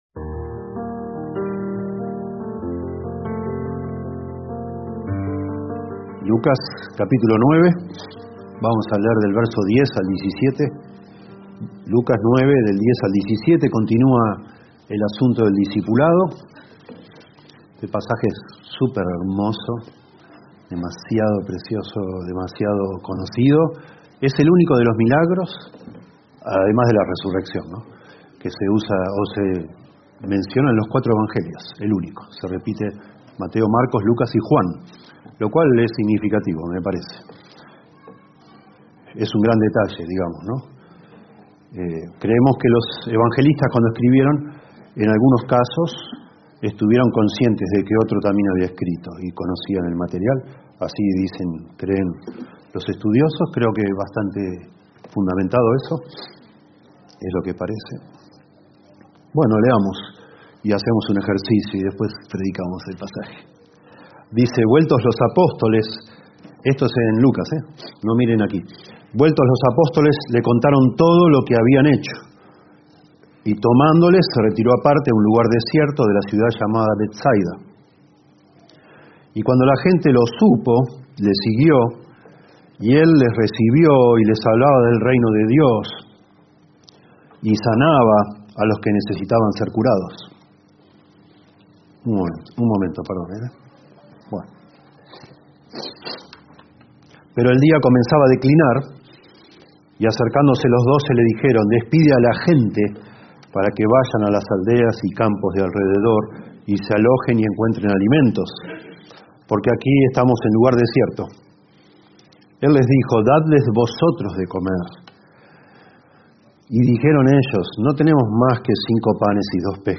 Reina-Valera 1960 (RVR1960) Video del Sermón Audio del Sermón Descargar audio Temas: